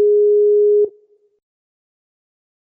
Звуки Skype
Звук гудка в Skype при звонке